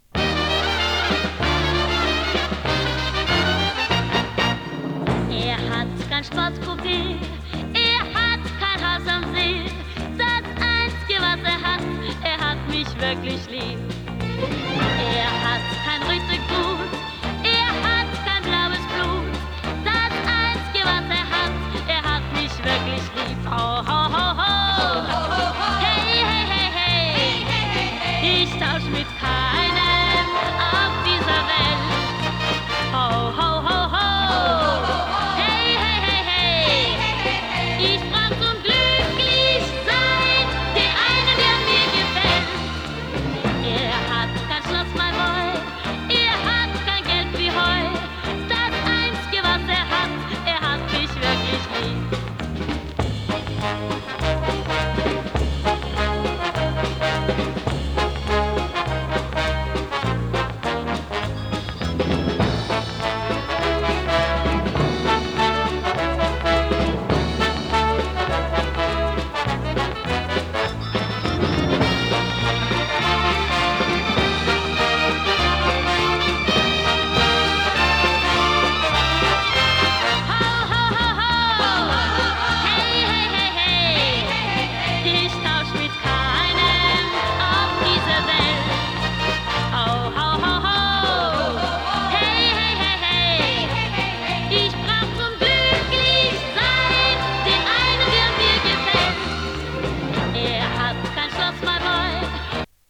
ヘイヘイヘイ、ホーホーホー・コーラスがキャッチーなポップチューン